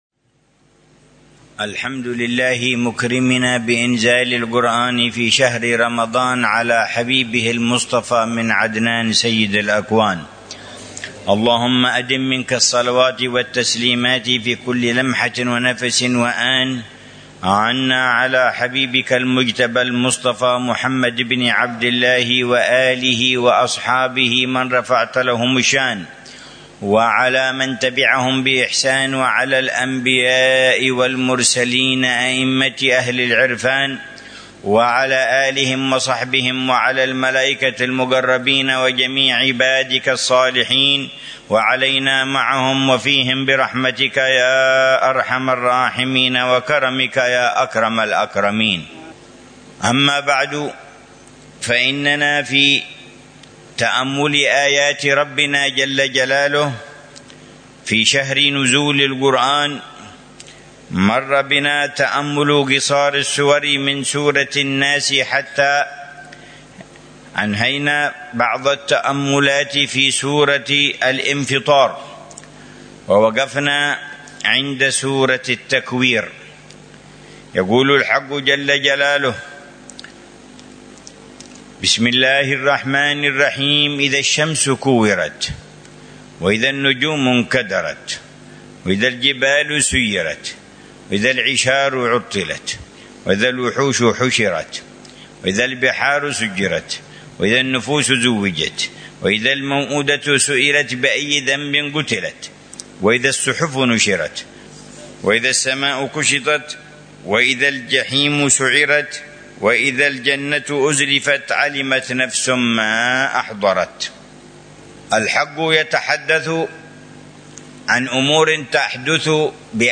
يواصل الحبيب عمر بن حفيظ تفسير قصار السور، موضحا معاني الكلمات ومدلولاتها والدروس المستفادة من الآيات الكريمة، ضمن دروسه الرمضانية في تفسير جز